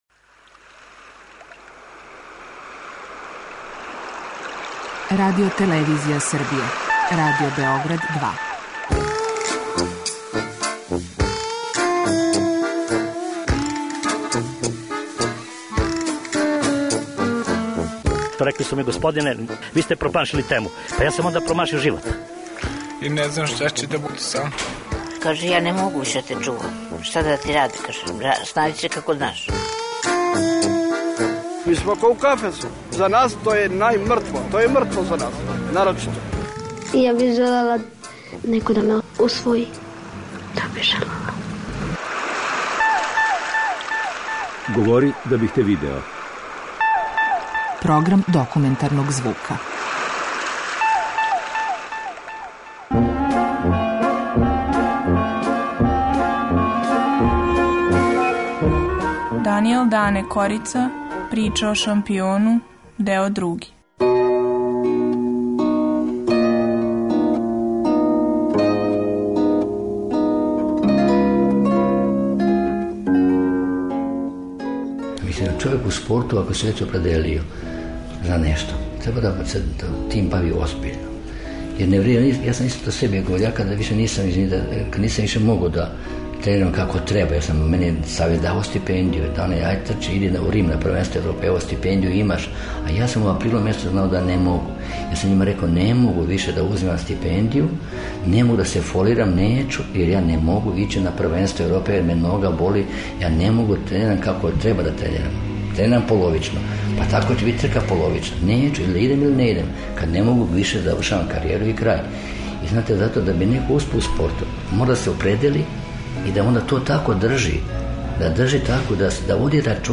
Dokumentarni program